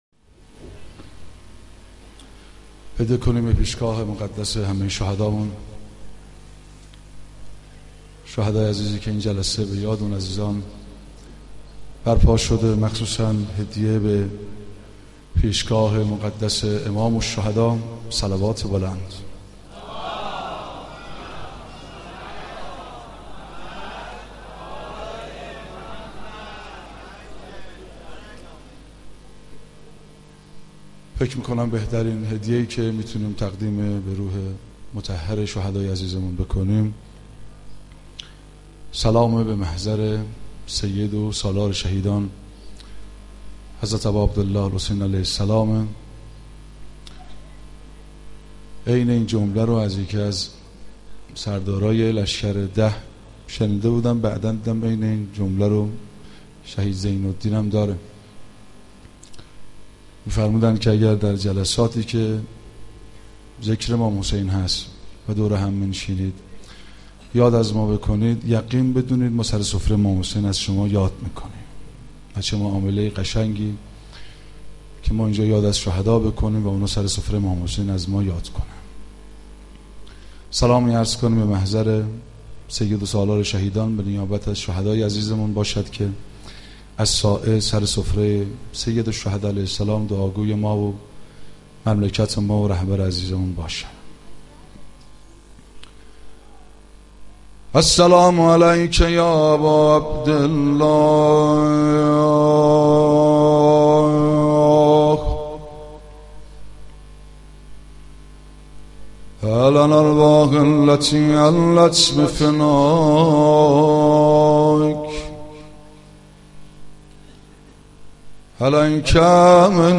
یادواره شهدای عملیات کربلای پنج در مسجد پنبه چی